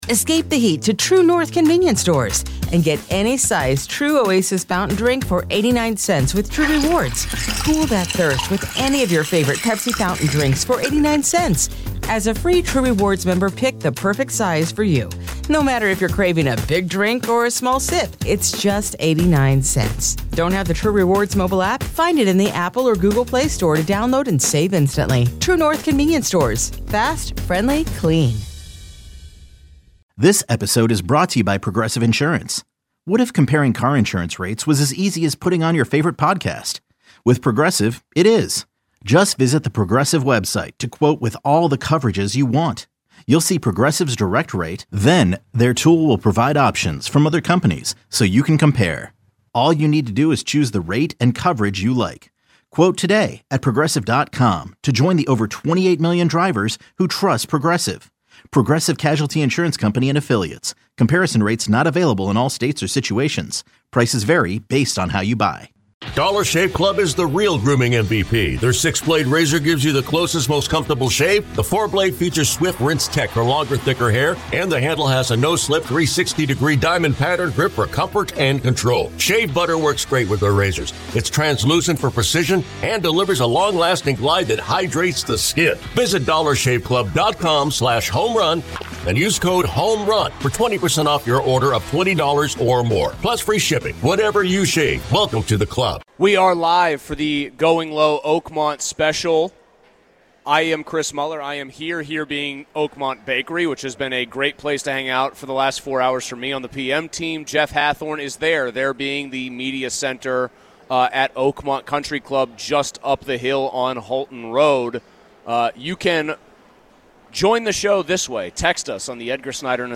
The guys discuss J.J Spaun's breakout day too, and we hear from him, as well.